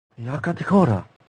Worms speechbanks
takecover.wav